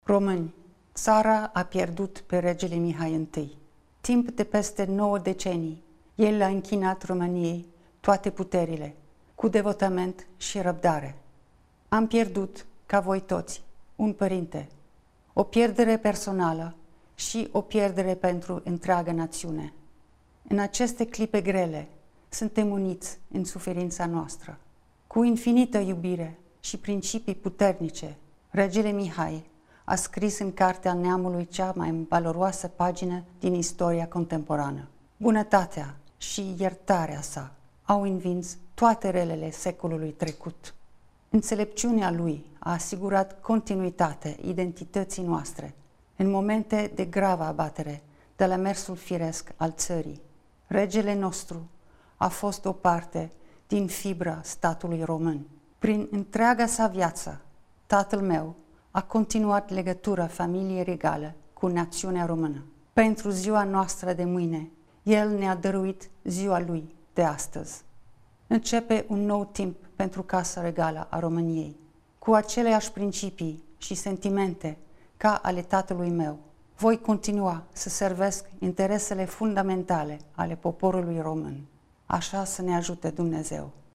Într-o declaraţie către ţară, Principesa Margareta, custodele Coroanei, deplânge dispariţia fostului monarh: